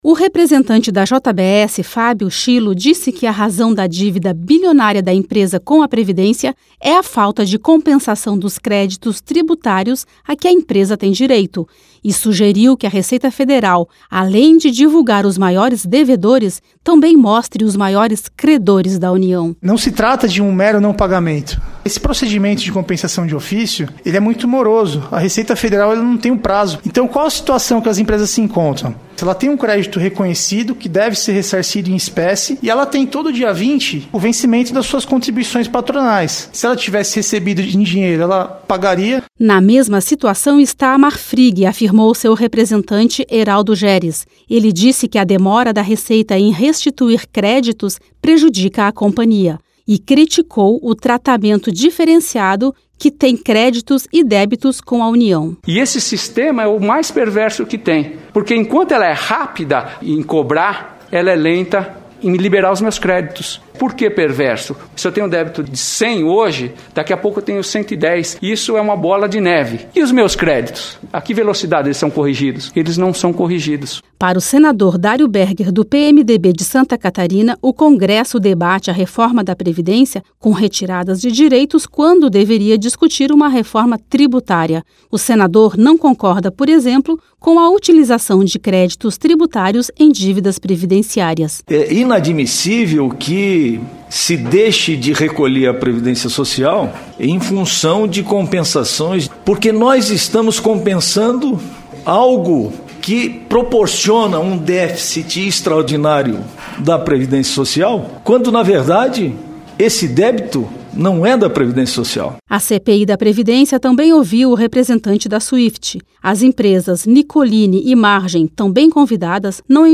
A demora da Receita Federal em compensar créditos tributários resulta em dívidas com a Previdência Social. Esta foi a justificativa apresentada pelos representantes dos frigoríficos que mais têm débitos previdenciários durante audiência pública da CPI da Previdência.